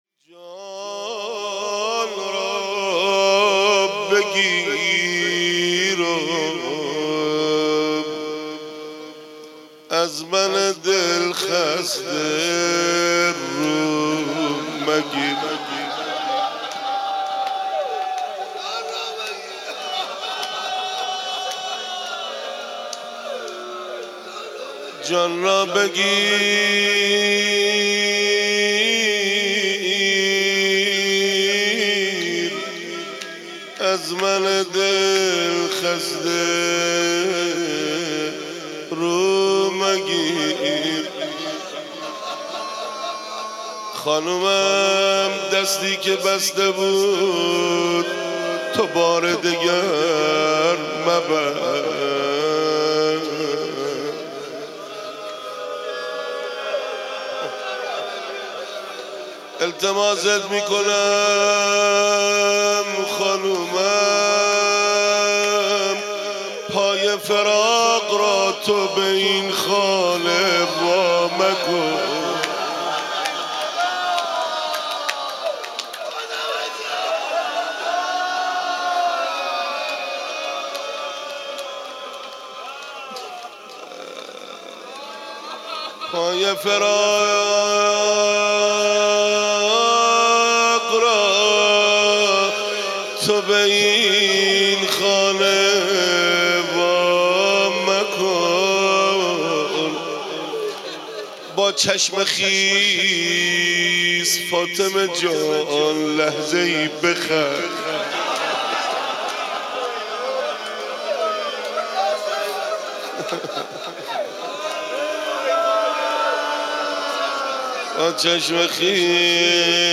خیمه حضرت فاطمه زهرا سلام الله علیها